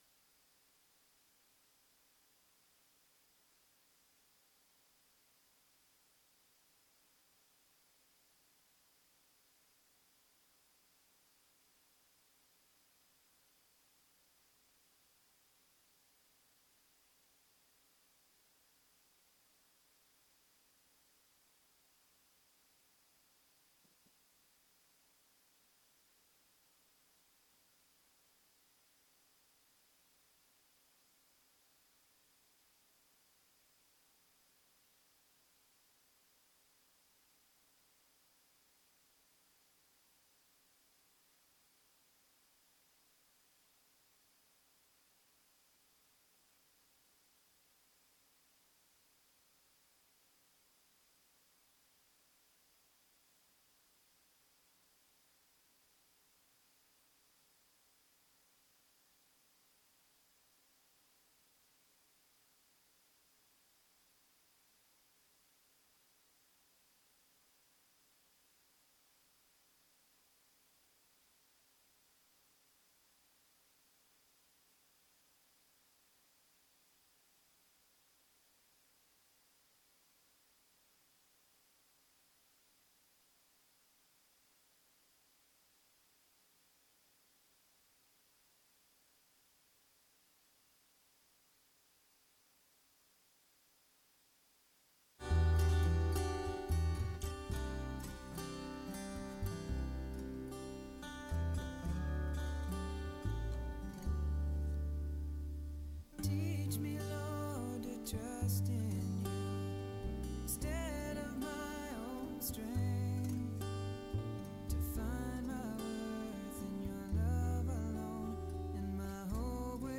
January 29 Worship Audio – Full Service